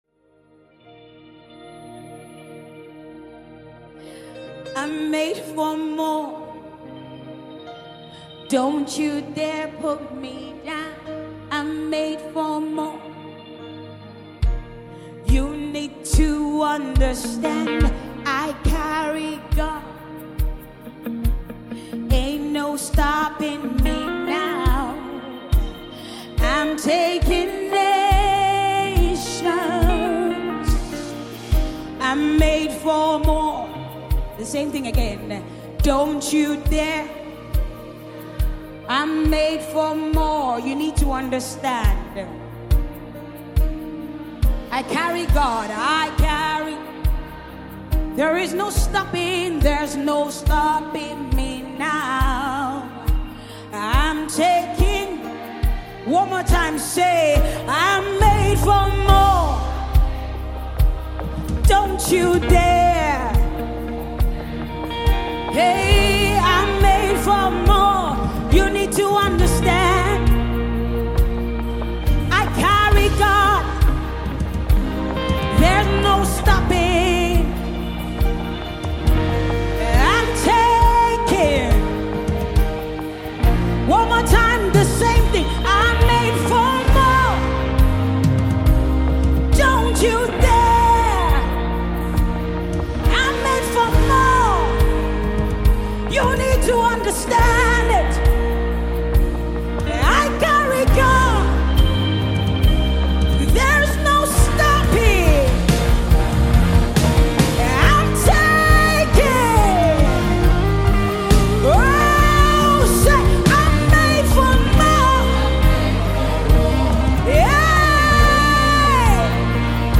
Famous Nigerian gospel singer